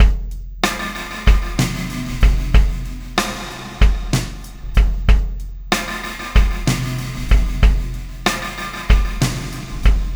Pulsar Beat 14.wav